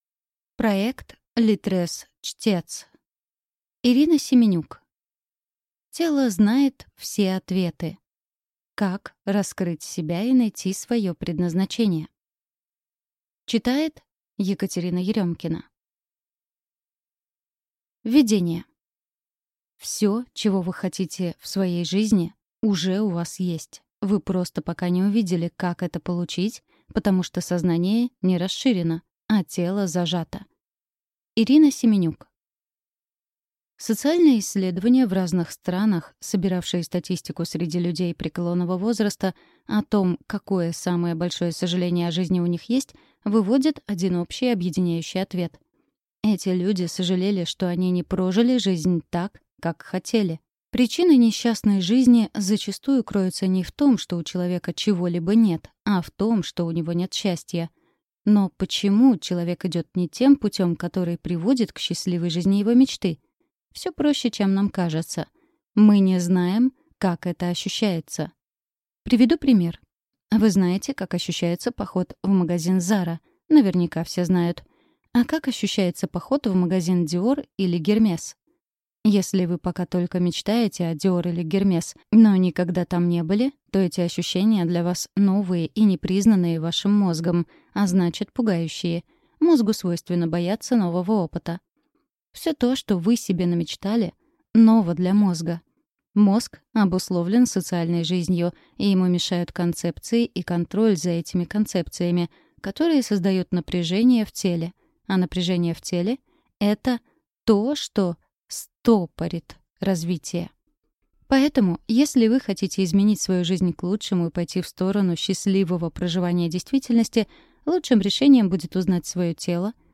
Аудиокнига Тело знает все ответы. Как раскрыть себя и найти свое предназначение | Библиотека аудиокниг